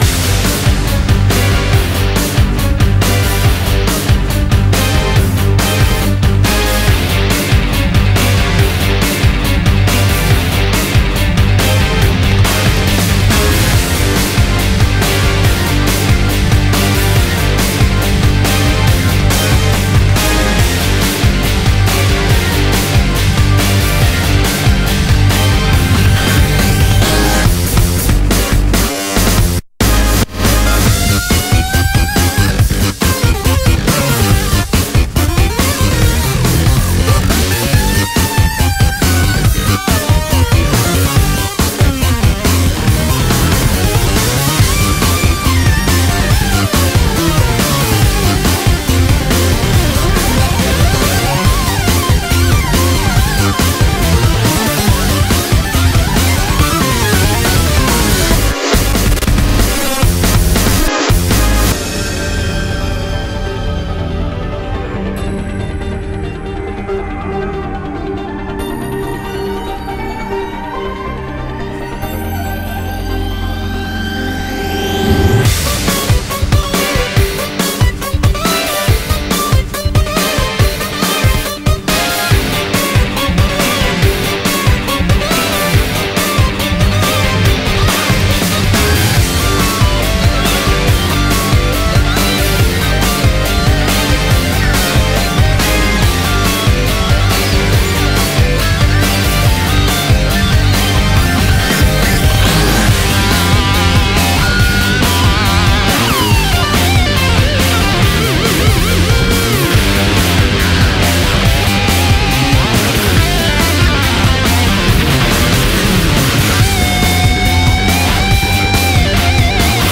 BPM70-140